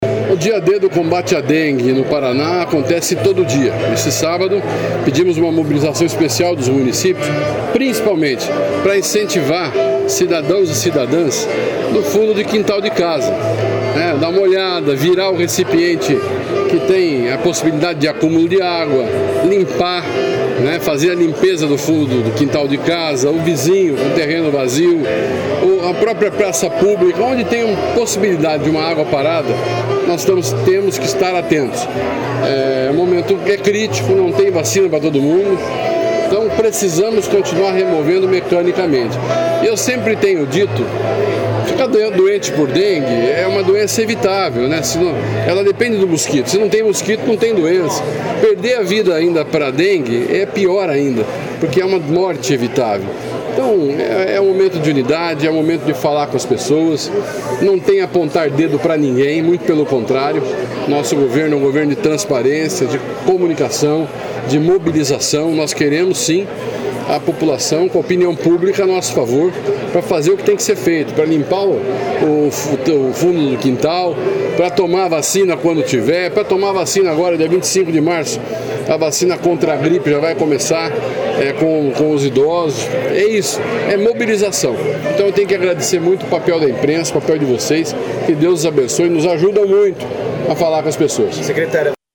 Sonora do secretário Estadual da Saúde, Beto Preto, sobre o Dia D de combate à dengue